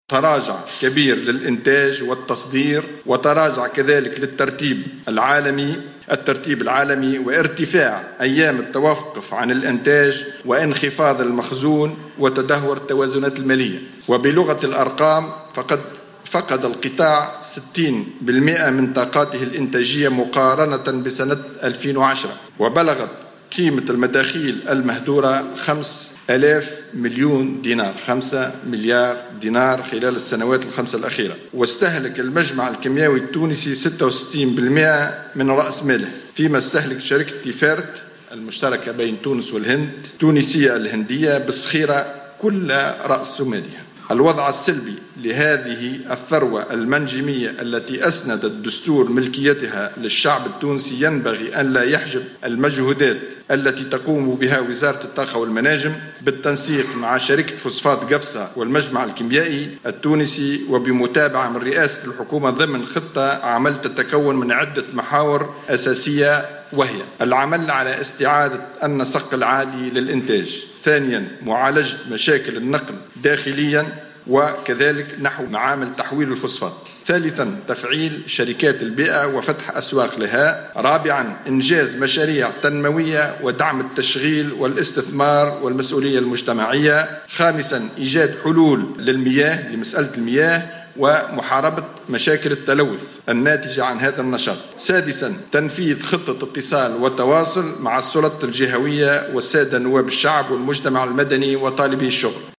أعلن وزير الطاقة والمناجم منجي مرزوق خلال ندوة صحفية بقصر الحكومة بالقصبة اليوم الخميس 14 جويلية 2016 عن تراجع كبير في التصدير والإنتاج وفي الترتيب العالمي لتونس إلى جانب انخفاض المخزون وتدهور التوازنات المالية.